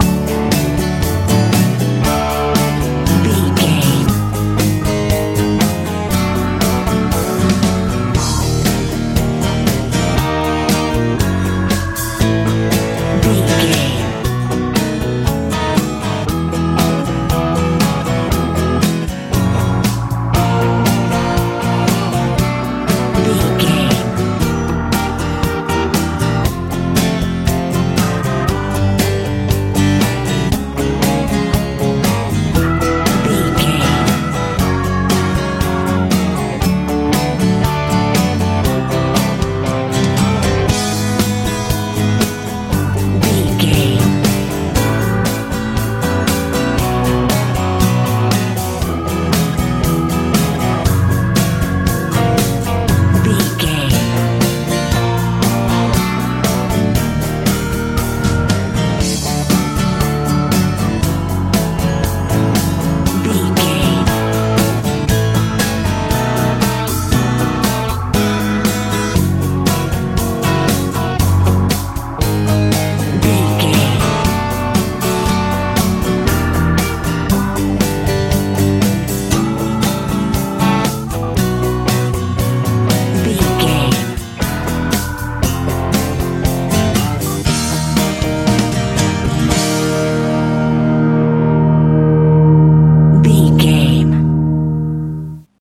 stripped down country feel
Ionian/Major
D
hopeful
peaceful
organ
acoustic guitar
bass guitar
drums
electric guitar
southern
lively